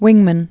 (wĭngmən)